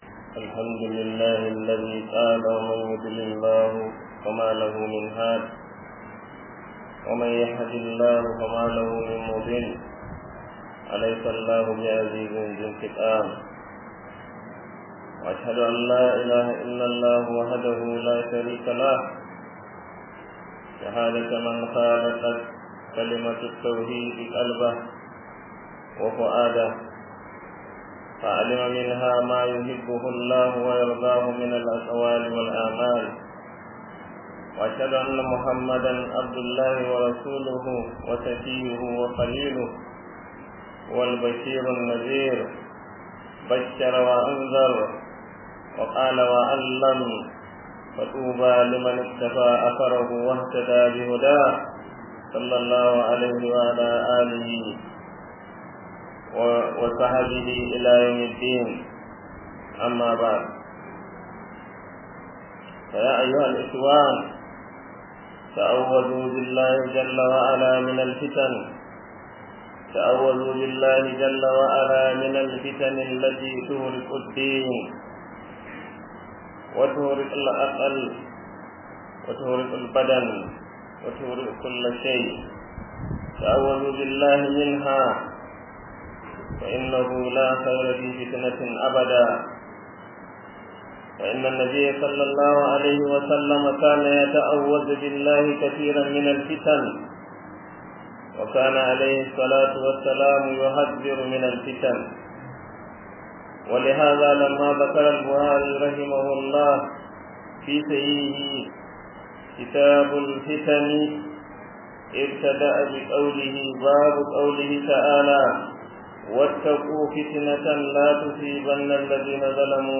Kutba 11-10-19.mp3